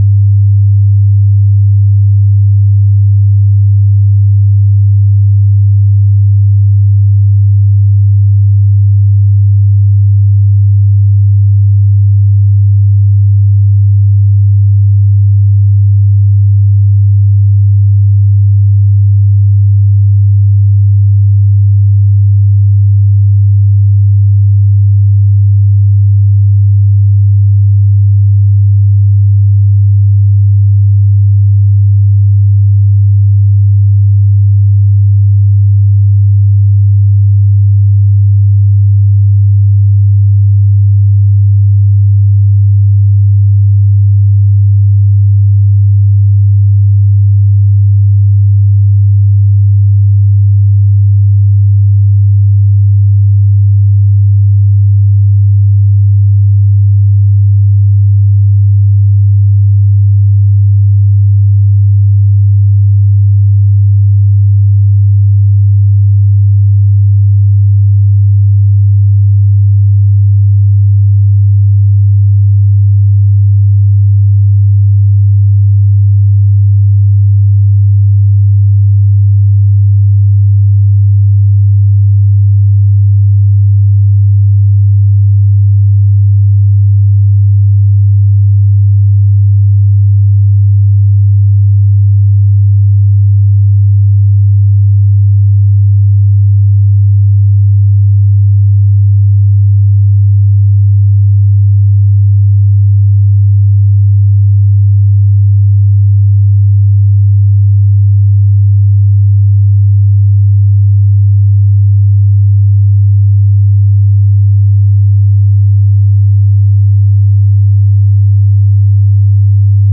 1800sec_monotone_klangwelt.mp3